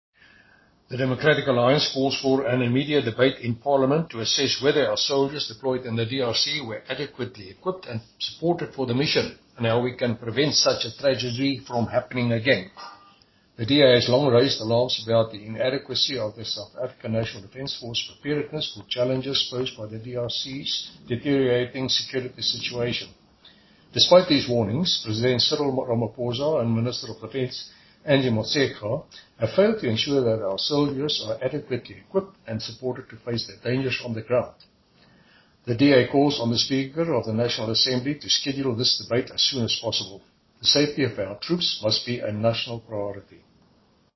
Issued by Chris Hattingh MP – DA Spokesperson on Defence & Military Veterans
Note to Editors: Please find attached soundbites in